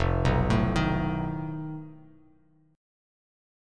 F7b5 chord